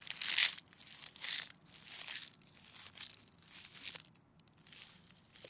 Walking through woods
描述：Me walking through woods for a while with twigs snapping and leaves crunching.
标签： crackle crunch foley leaf leaves walk
声道立体声